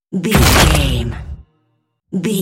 Dramatic deep hit bloody
Sound Effects
heavy
intense
dark
aggressive
hits